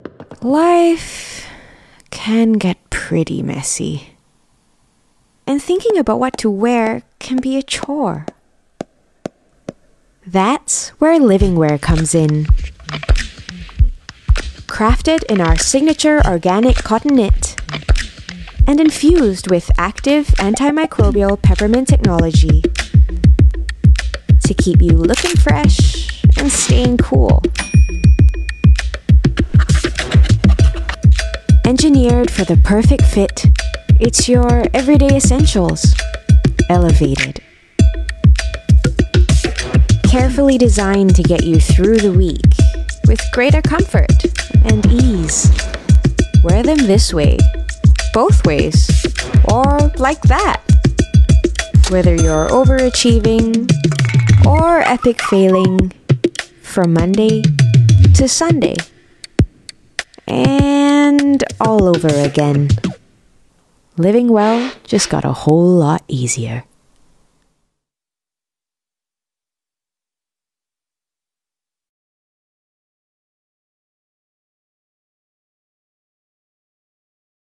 Voice Samples: Living Wear
female
EN Asian